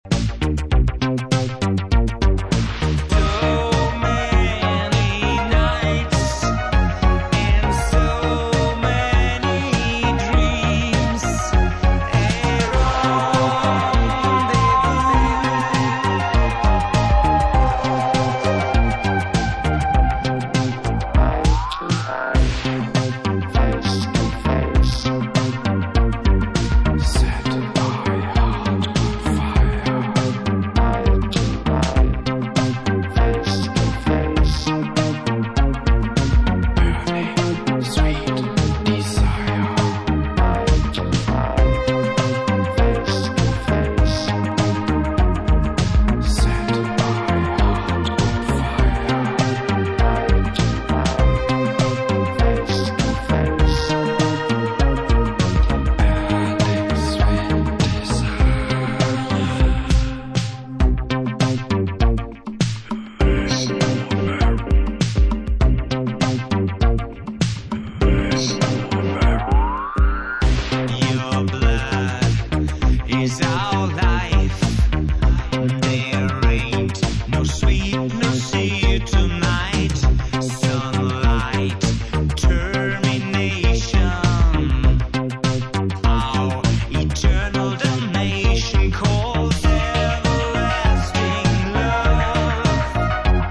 Disco Electro